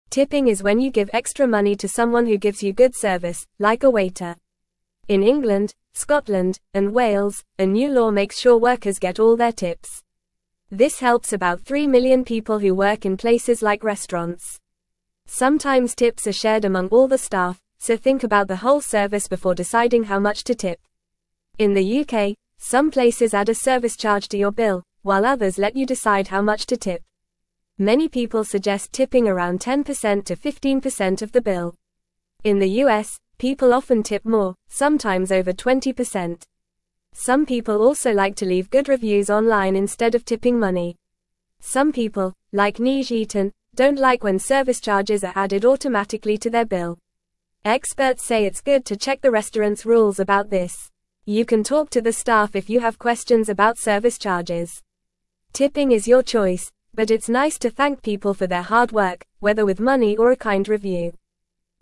Fast
English-Newsroom-Lower-Intermediate-FAST-Reading-Tips-and-Service-Charges-How-to-Say-Thanks.mp3